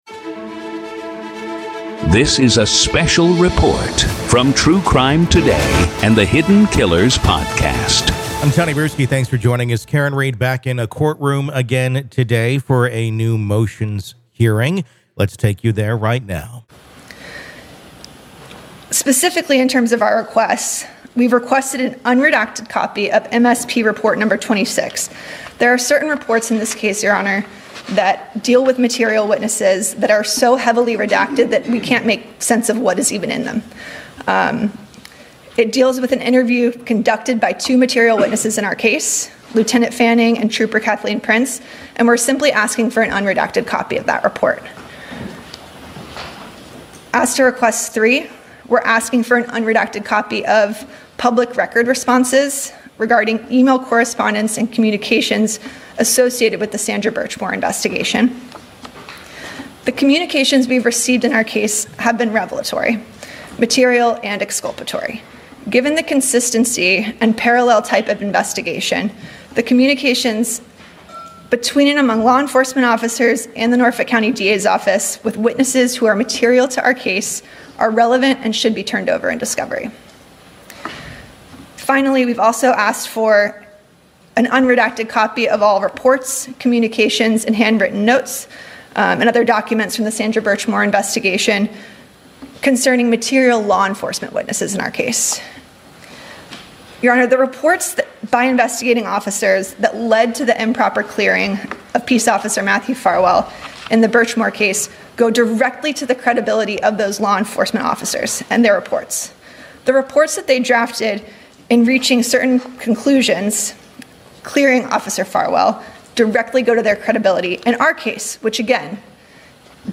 RAW COURT AUDIO: Judge Halts Karen Read Pretrial Hearing After ‘Grave Concern’ Over New Information PART 2